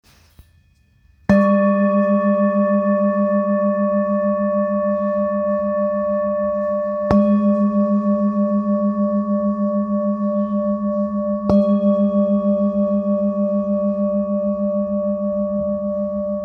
Handmade Singing Bowls-31750
Singing Bowl, Buddhist Hand Beaten, with Fine Etching Carving of Mandala, Select Accessories
Material Seven Bronze Metal